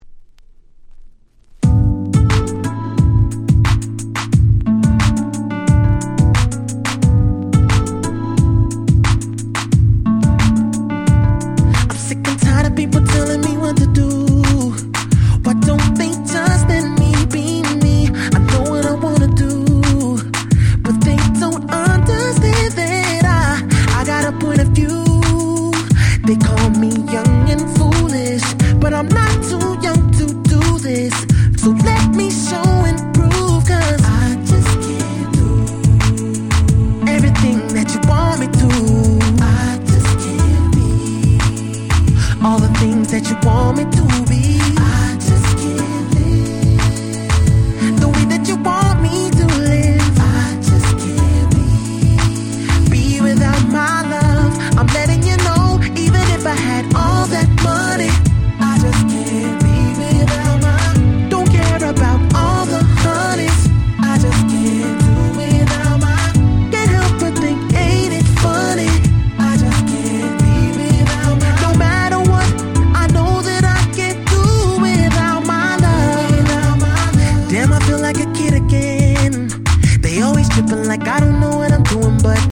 06' Nice UK R&B !!